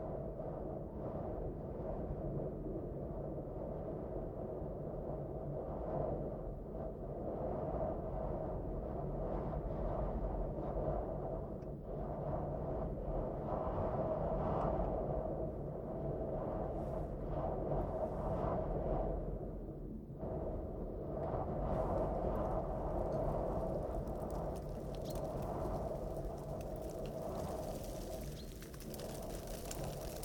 Cette création sans commentaire ni musique accompagnera les auditeurs durant 72 minutes à l'écoute des plus belles découvertes sonores au fil d'une année dans cette magnifique région qu'est le Diois en Drôme.
1 -- Naturophonie hivernale